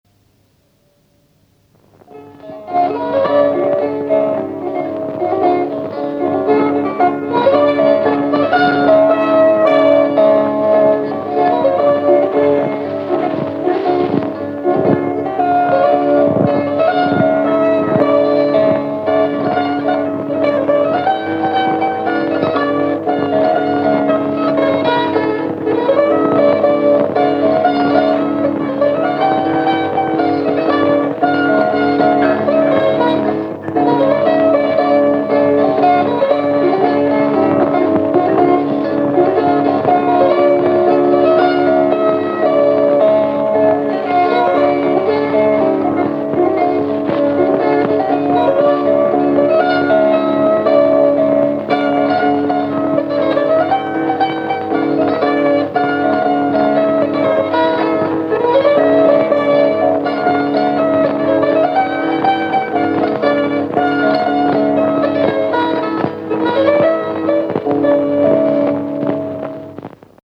Polka nr 4